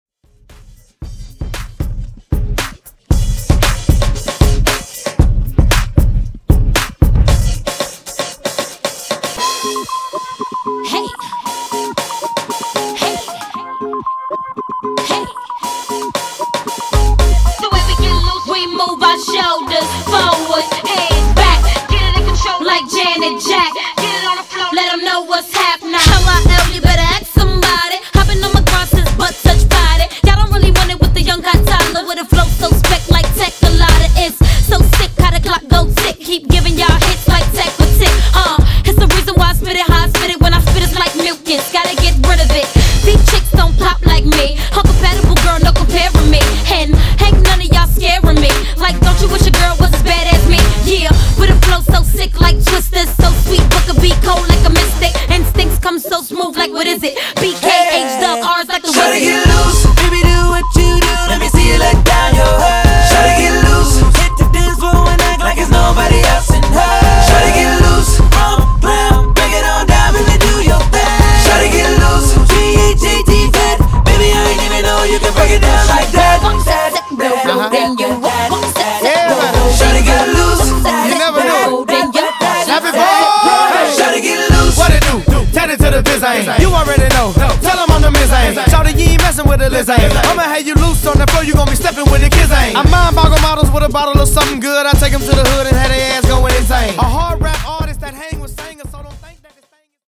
Genre: Version: BPM: 115 Time: 4:02